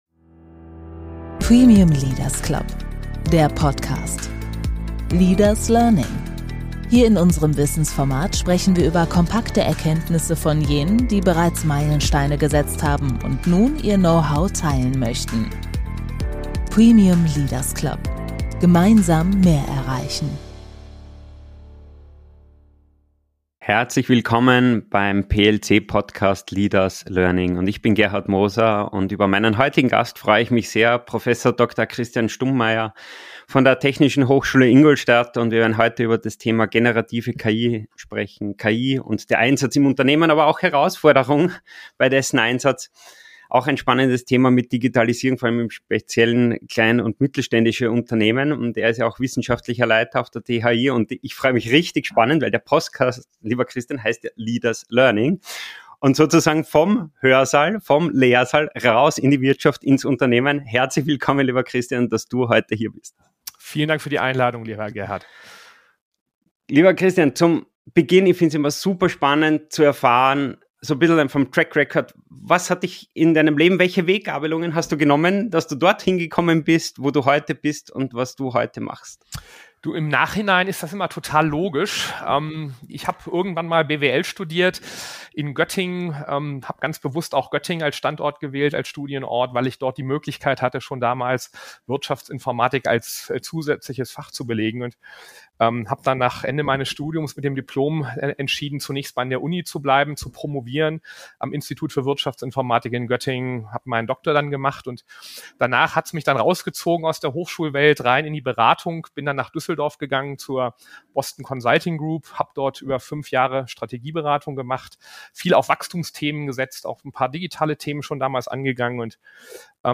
Er zeigt praxisnah auf, wie Künstliche Intelligenz heute bereits in Marketing, Vertrieb und Service genutzt wird – und wo ihre Grenzen liegen. Zudem diskutieren die beiden den EU AI Act, ethische Herausforderungen und die Frage, ob KI bald „zu klug“ für den Menschen wird. Ein inspirierendes Gespräch für alle, die Digitalisierung nicht nur verstehen, sondern auch gestalten wollen.